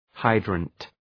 Προφορά
{‘haıdrənt}